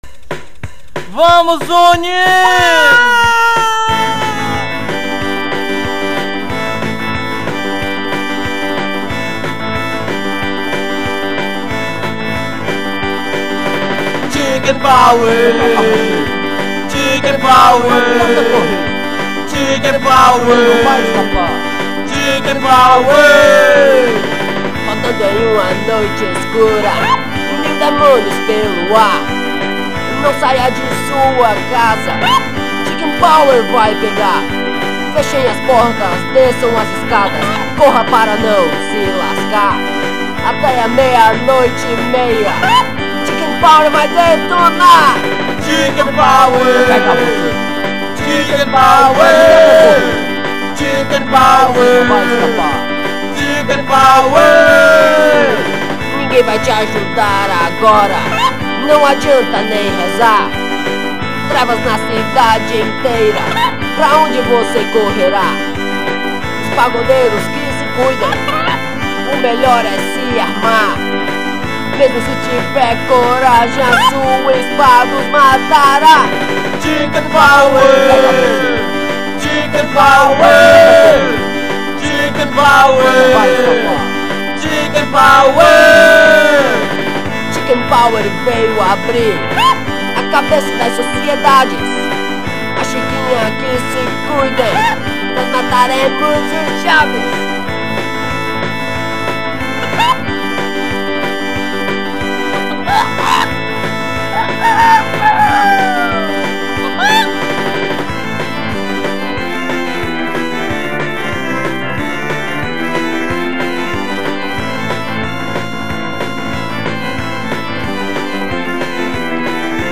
EstiloParódia / Comédia